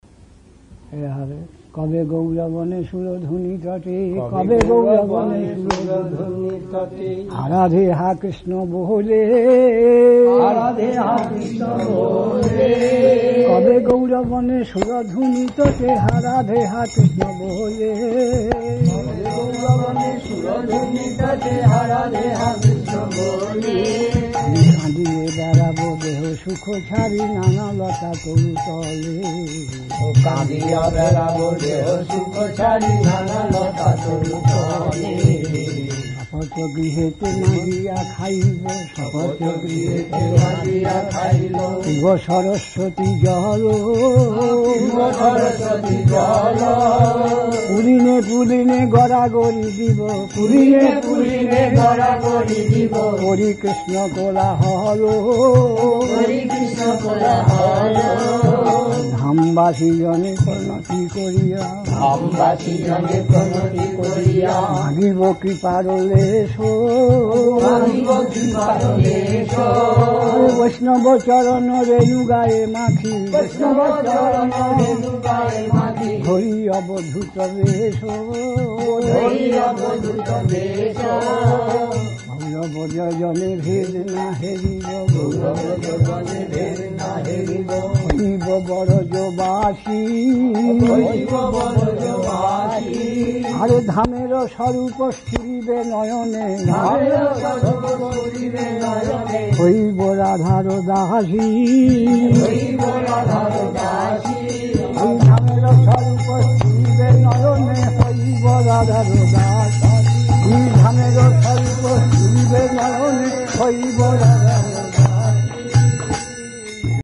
We apologise for the audio quality. It's being posted as vintage classic recordings.
Киртан